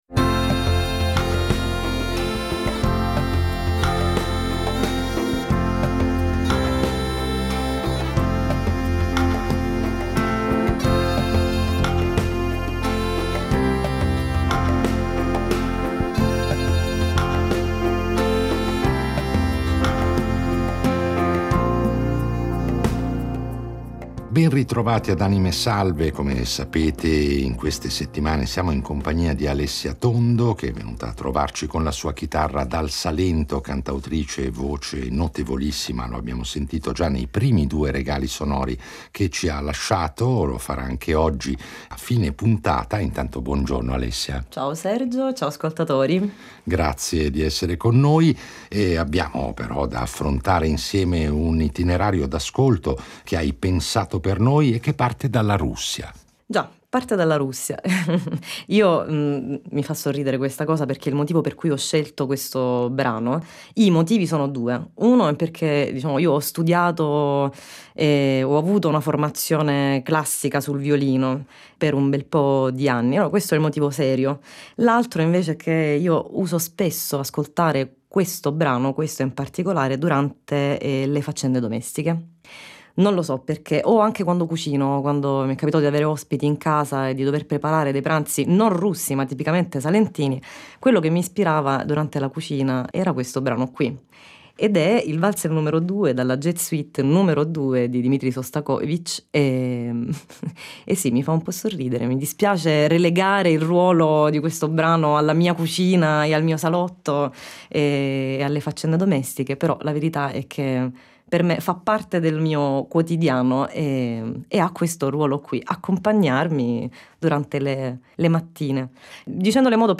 È ormai imminente l’uscita del suo primo album da solista, del quale ascolteremo senz’altro alcuni esempi con esecuzioni esclusive, realizzate solo per noi.